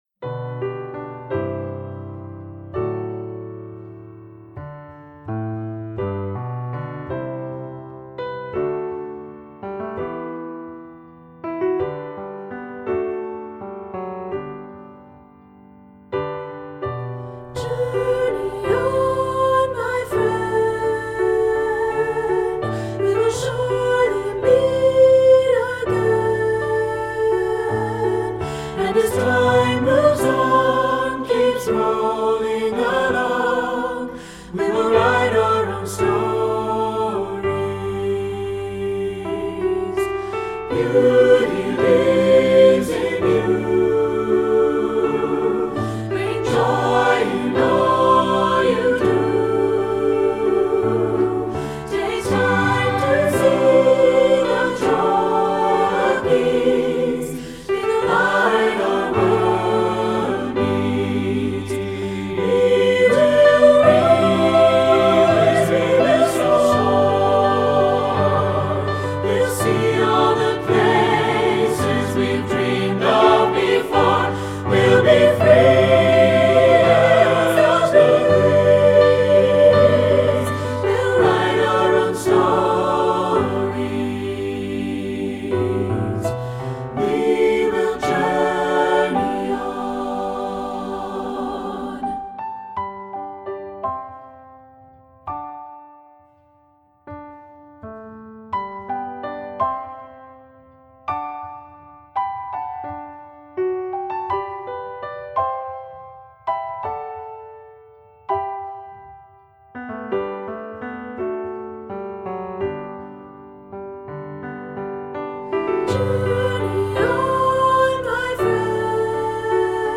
Choral Concert/General
SATB
SATB Audio